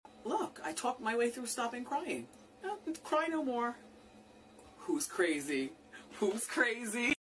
wendy williams saying she talked sound effects free download